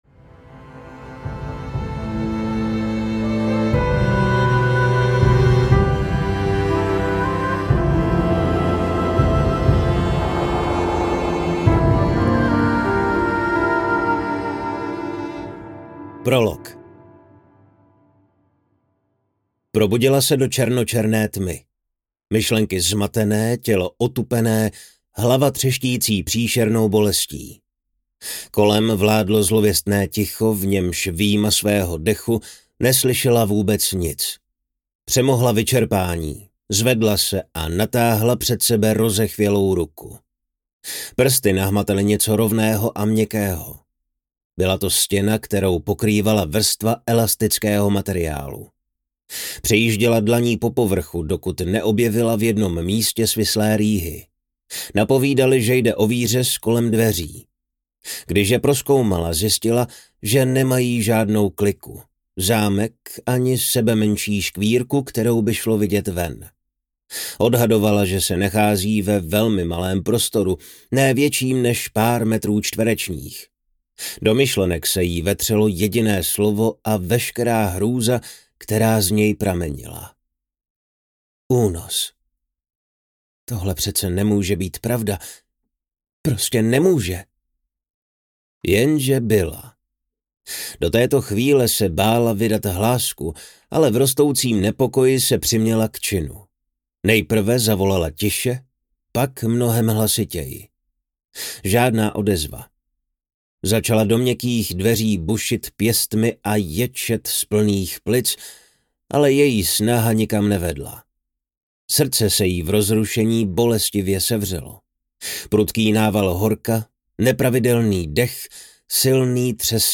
Zresetuj mě audiokniha
Ukázka z knihy